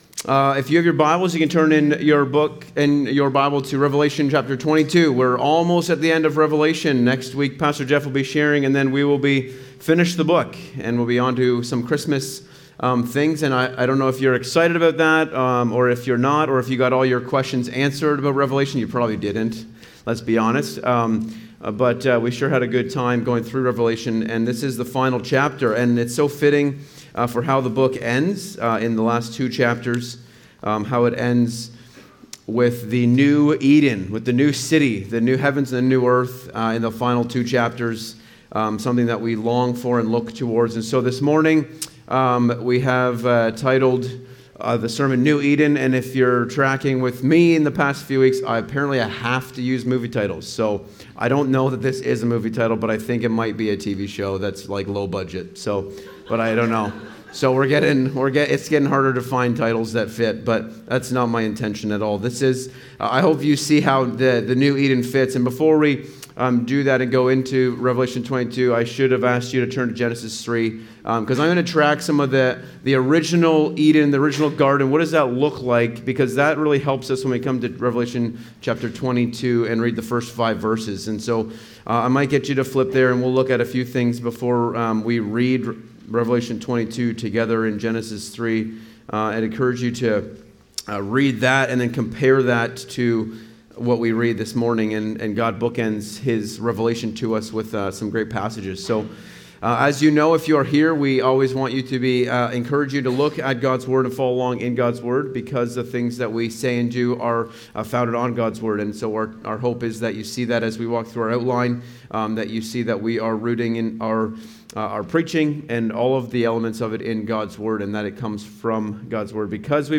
preaches from Esther 9:1-19.